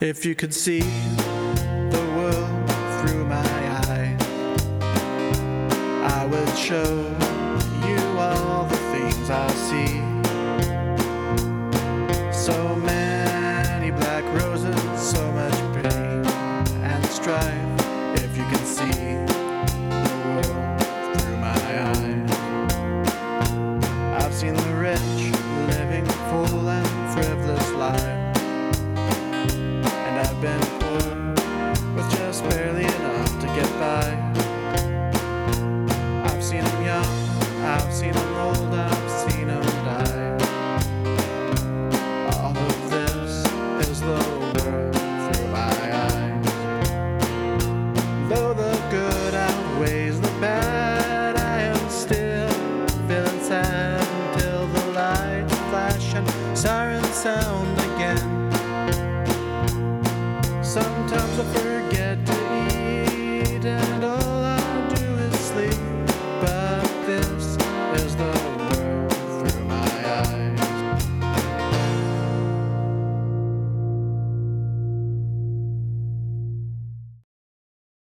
Here is my song submission for the furry_musicians contest.
country folk rock
You have a good voice bruv, this is quite a jam!